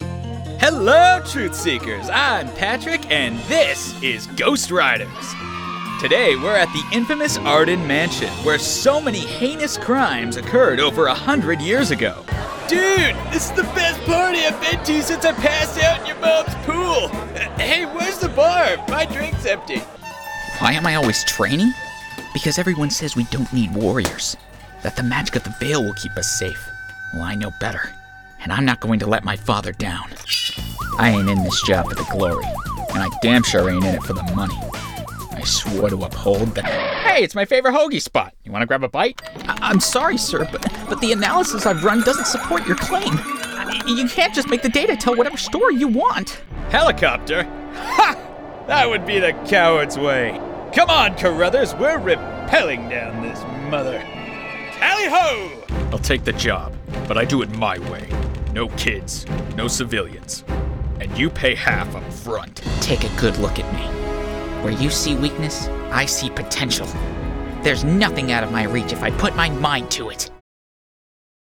Young Adult, Adult
Fun, sporty, expressive and ready to help!
british rp | character
standard us | natural
ANIMATION 🎬